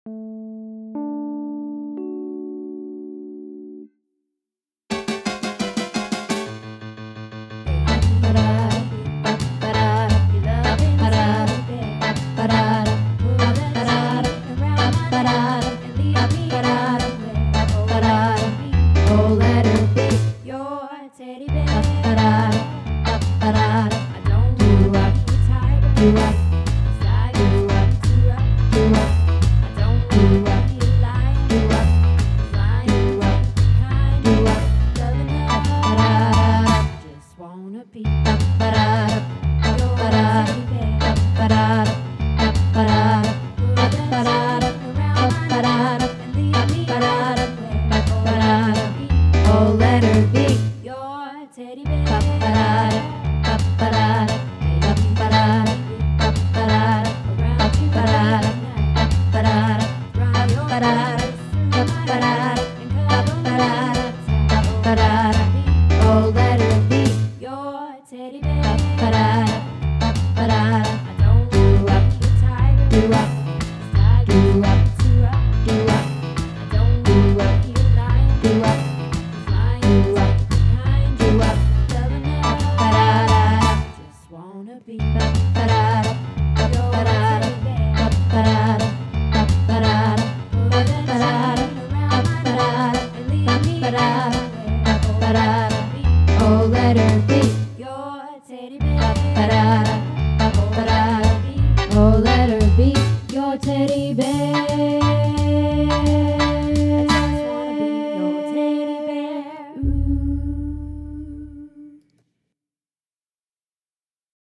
Teddy Bear - Bass